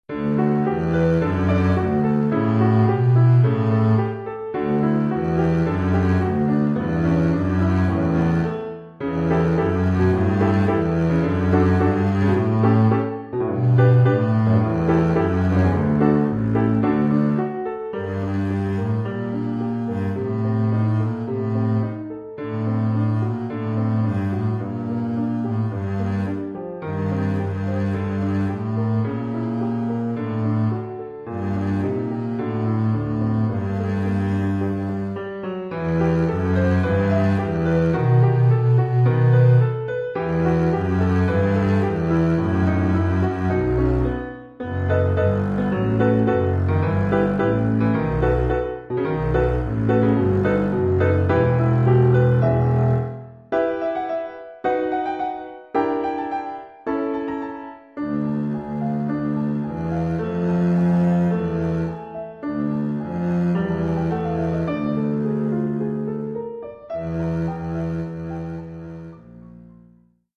Formule instrumentale : Contrebasse et piano
Oeuvre pour contrebasse
avec accompagnement de piano.
Niveau : débutant (1er cycle, 1ère année).